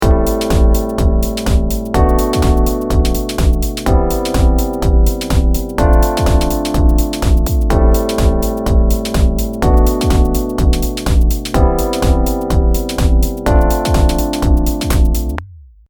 まぁなんてことはないバッキングトラックです。コードを奏でている音を聴いてみましょう。